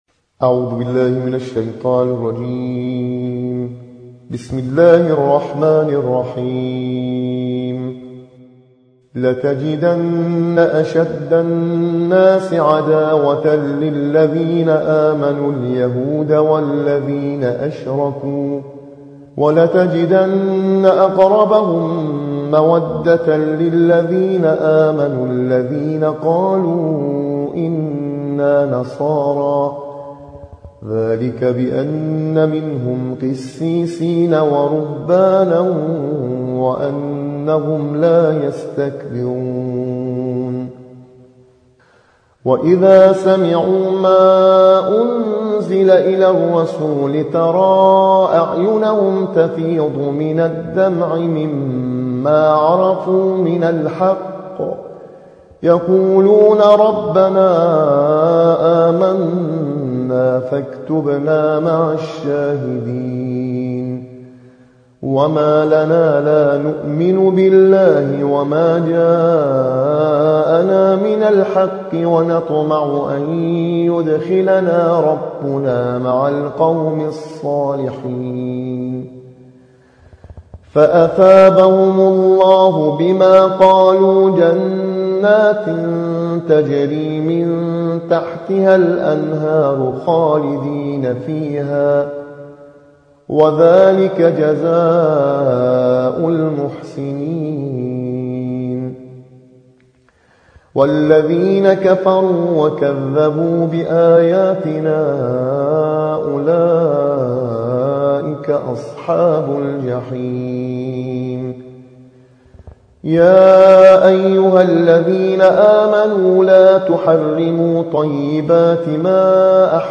صوت | ترتیل‌خوانی